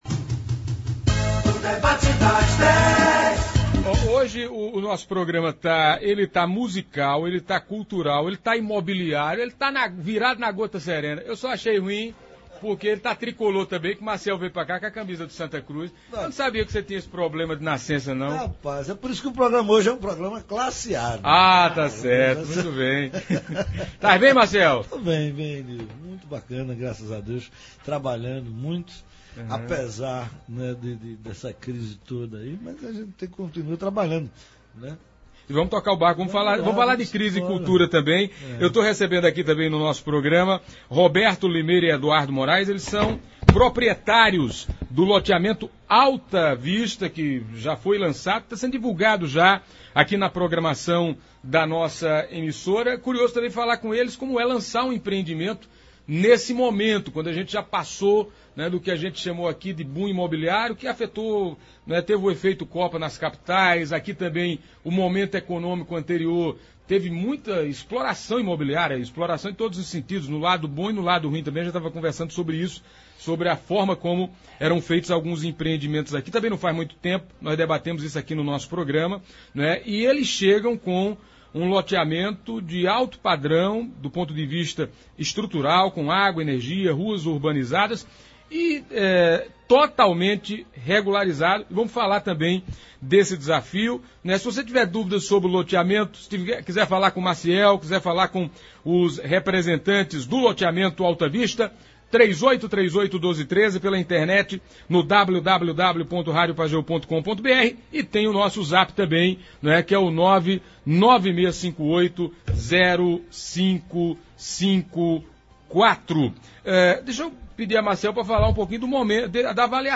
O cantor e compositor Maciel Melo esteve hoje no Debate das Dez da Pajeú. Maciel esteve falando de seus projetos e também na condição de garoto propaganda de um investimento imobiliário em Tabira, que será lançado dia 5 de dezembro, o Alta Vista.